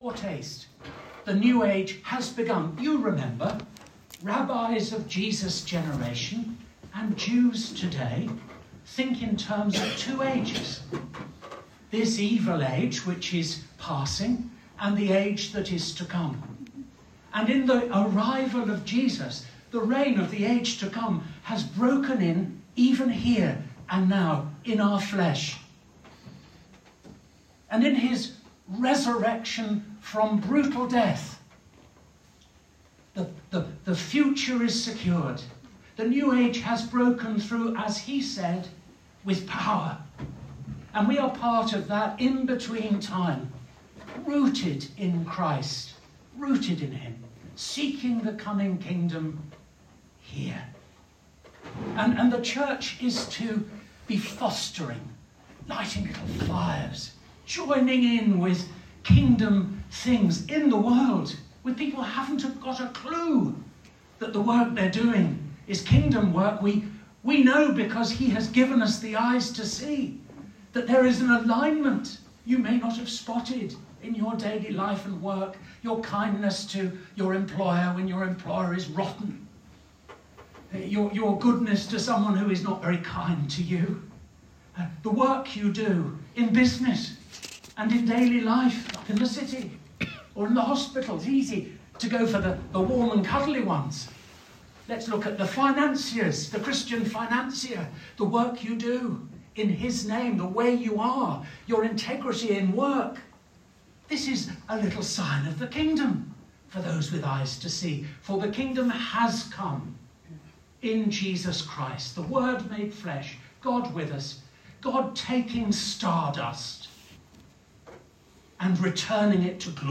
Bishop Jonathan spoke during the Diocesan Synod Eucharist, taking as his text the story of the Prodigal Son. He challenged synod members to decide: where is your hope anchored?
Bishop_Jonathan_Presidential_Address.m4a